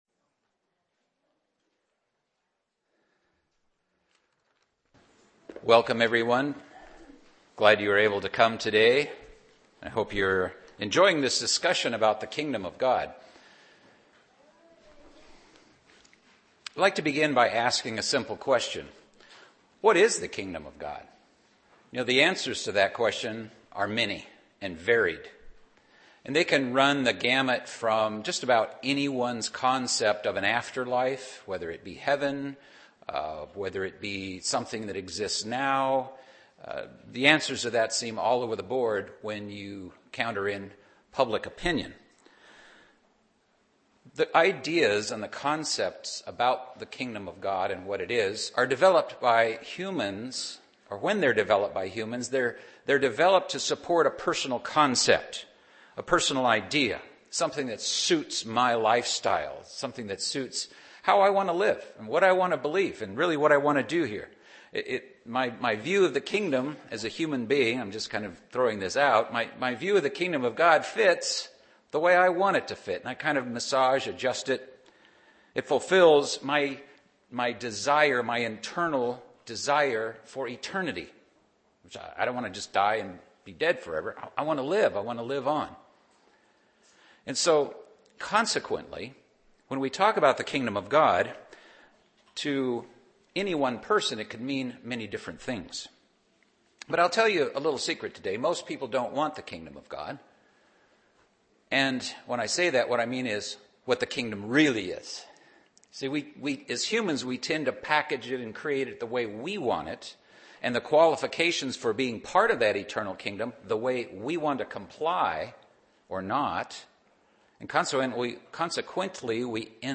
Learn more in this Kingdom of God seminar.